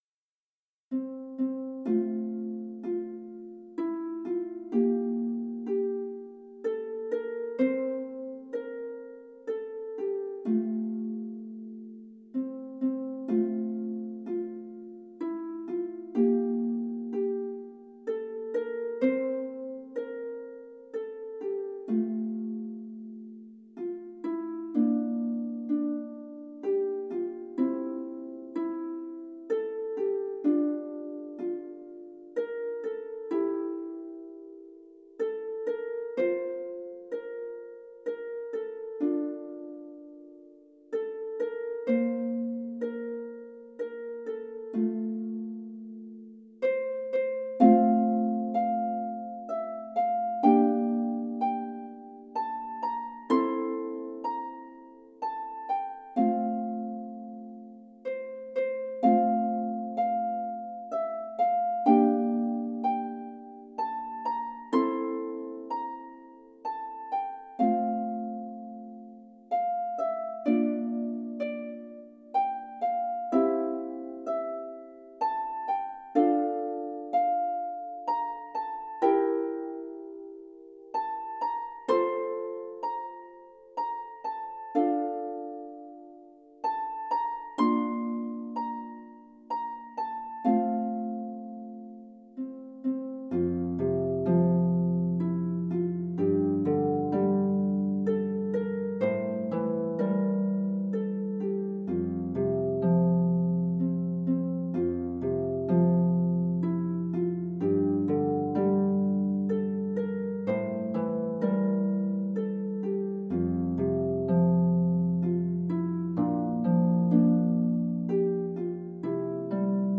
traditional Polish tune
solo lever or pedal harp